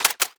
GUNMech_Reload_03_SFRMS_SCIWPNS.wav